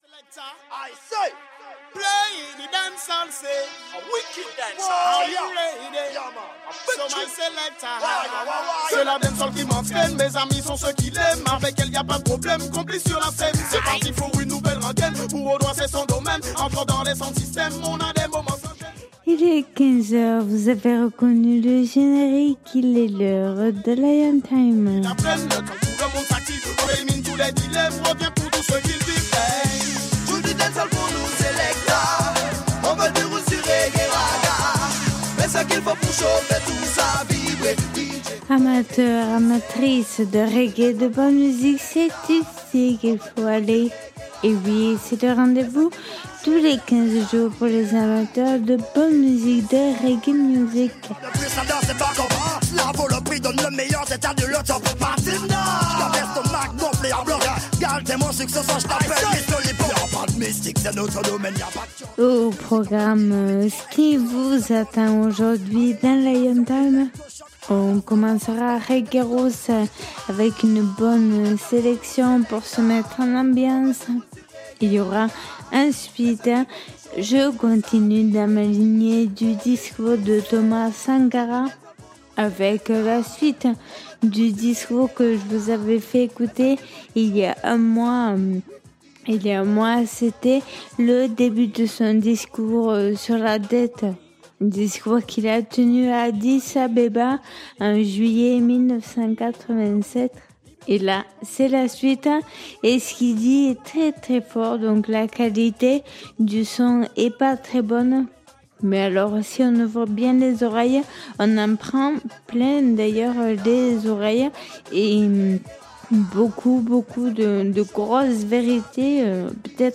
Émission qui invite au voyage et à la découverte du roots du reggae et du dancehall. Que vous soyez novices ou grands connaisseurs de la musique reggae et la culture rasta, cette émission permet d’écouter de la bonne musique et surtout des artistes de qualité qui sont très rarement programmés sur d’autres radios.